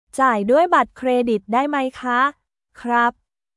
ジャイ ドゥアイ バット クレディット ダイ マイ カ／クラップ